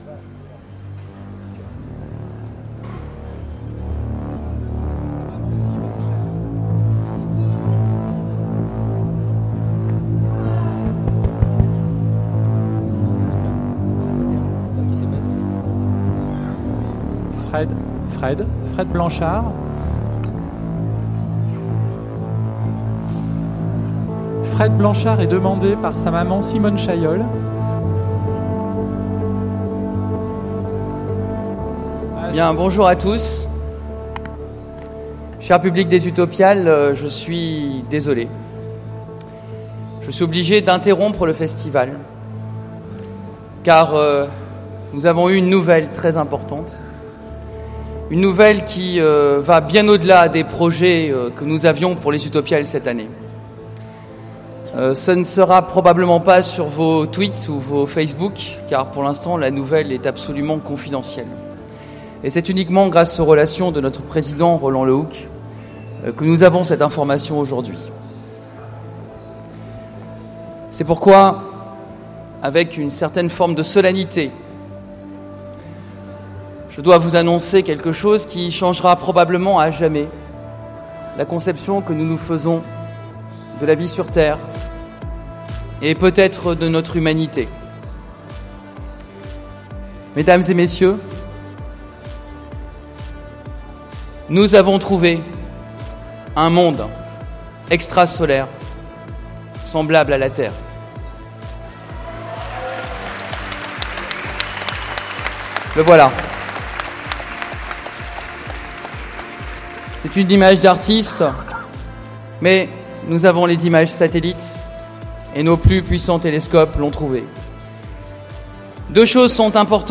Utopiales 2015 : Conférence Nous avons trouvé une exoplanète semblable à la Terre et accessible
Conférence